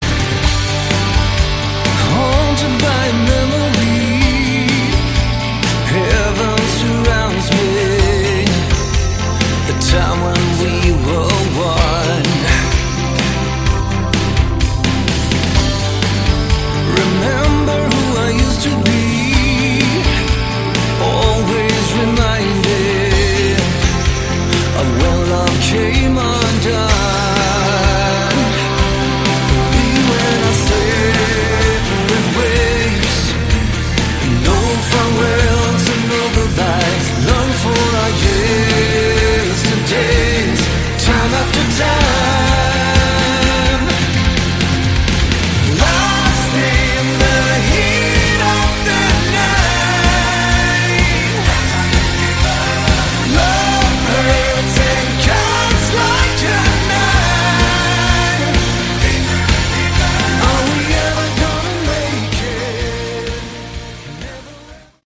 Category: Melodic Rock
lead and backing vocals, acoustic guitars
electric guitars
lead guitars
bass and backing vocals
keyboards
drums